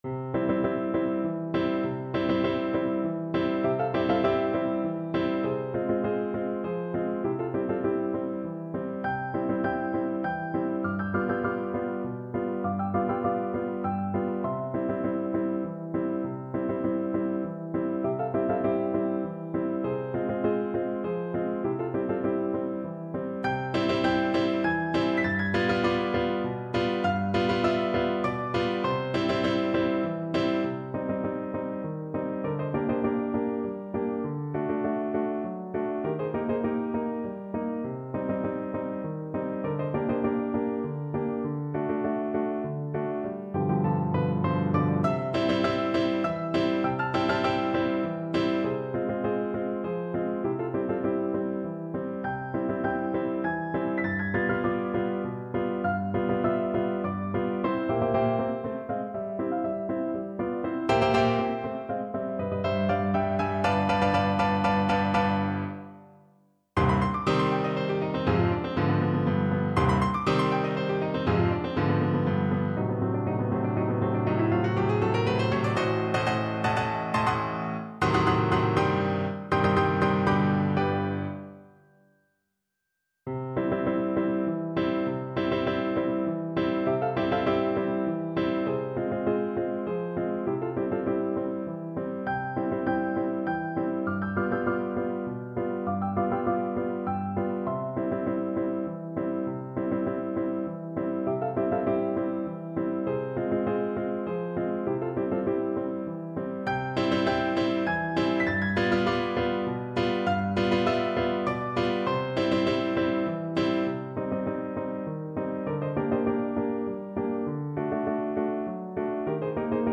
Tenor Voice
3/4 (View more 3/4 Music)
C major (Sounding Pitch) (View more C major Music for Tenor Voice )
Allegro = 100 (View more music marked Allegro)
Classical (View more Classical Tenor Voice Music)
di_quella_pira_TEN_kar1.mp3